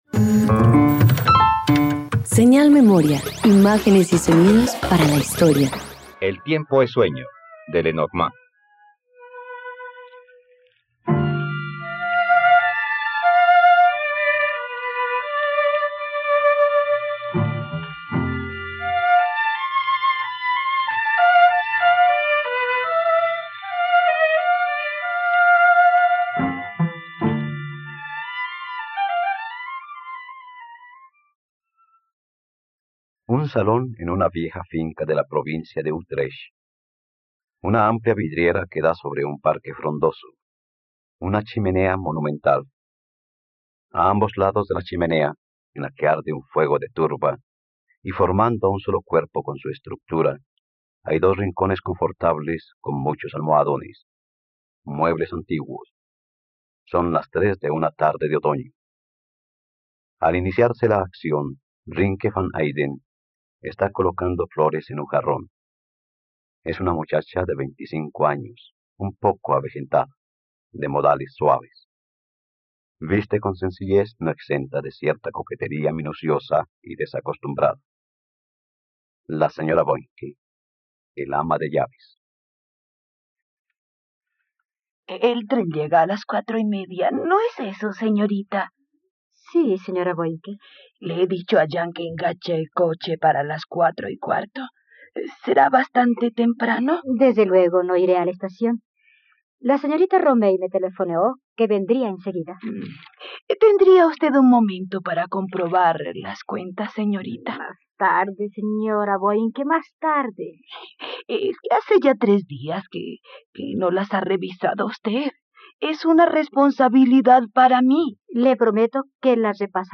..Radioteatro. Escucha la adaptación radiofónica de “El tiempo es sueño” de Henri-René Lenormand por la plataforma streaming RTVCPlay.